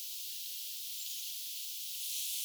pajusirkku?
Kuusi kertaa kuului pienen kahlaajarannan
läheisestä pajukkoruovikosta pajusirkun ääntä.
onko_pajusirkkulintu.mp3